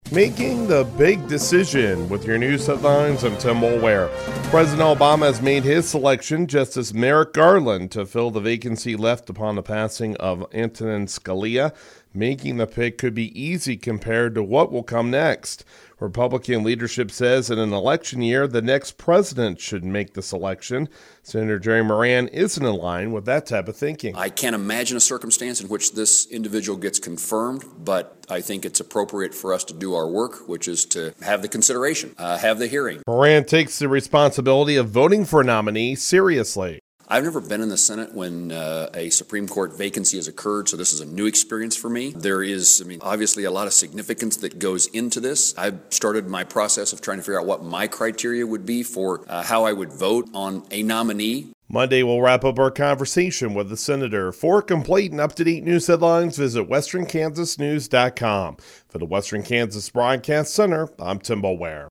*On-air story*